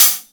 Q Hat clmx1 f.WAV